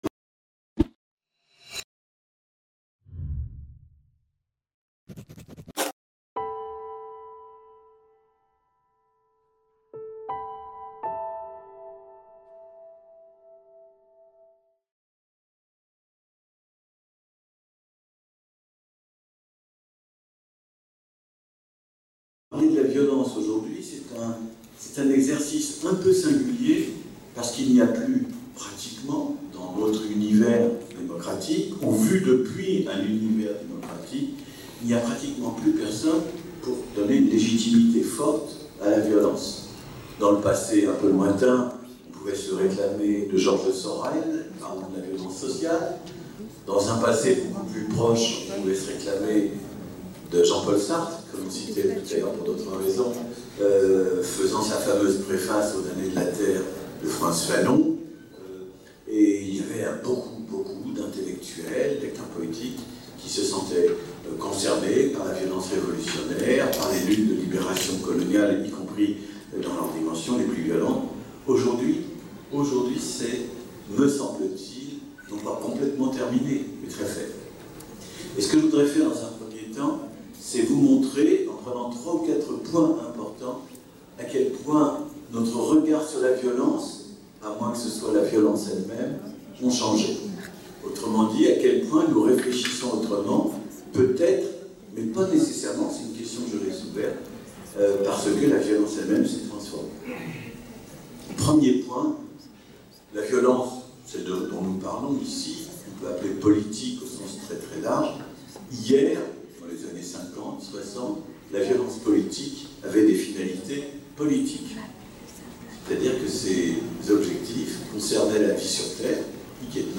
Conférence de Michel Wieviorka Colloque de Tunis du 3-4 Juillet 2017 Le Panel International sur la Sortie de la Violence s’est réuni à Tunis le 3 et 4 Juillet 2017. Le colloque s’est déroulé en partenariat avec l’Institut Tunisien des Etudes Stratégiques, l’Institut de recherche sur le Maghreb contemporain et l’United States Institute of Peace.